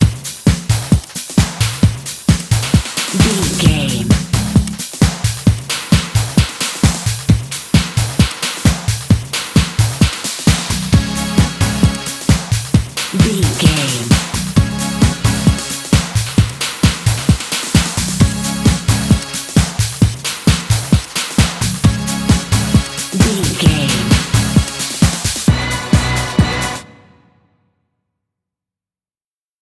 Fast paced
Ionian/Major
Fast
synthesiser
drum machine
Eurodance